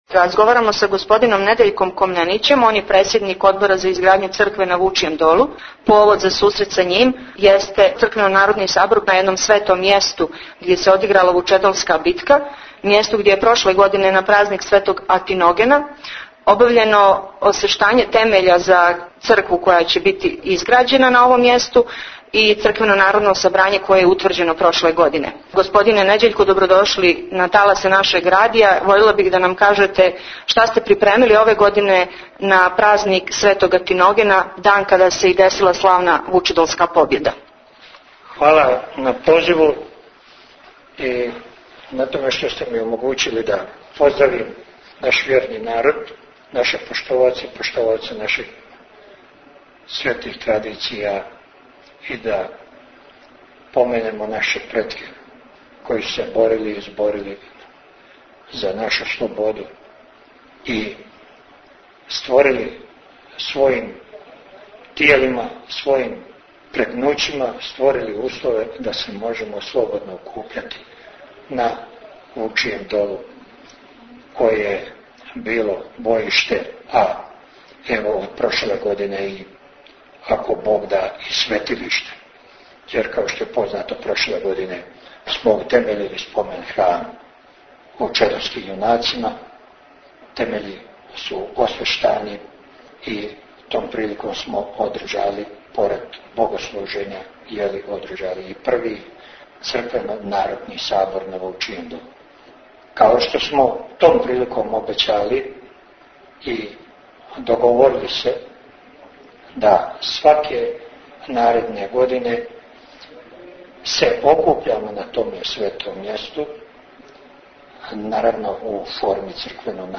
Извјештаји